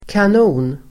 Uttal: [kan'o:n]